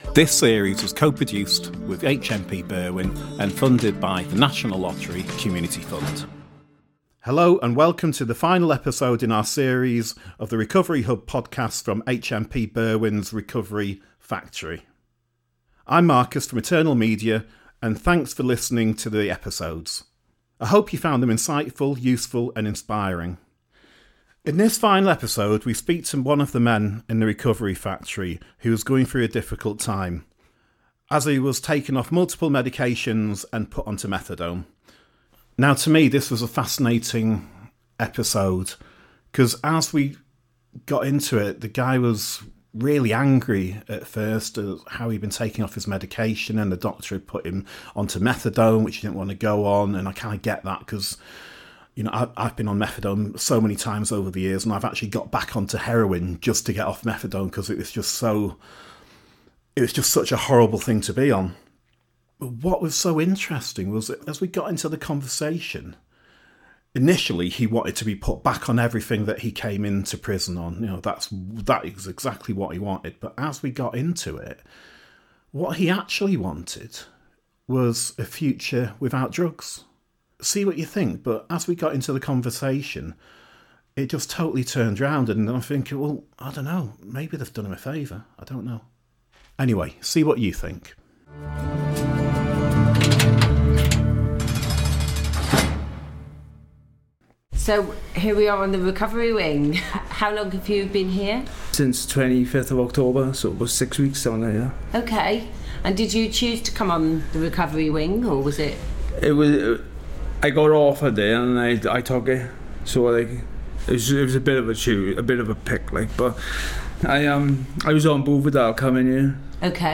RHP features conversations between people in recovery, intimate recounting of stories from addiction, finding recovery and how they live their lives in recovery. We also cover the topics of crime, rehabilitation, politics, and the science of addiction and recovery.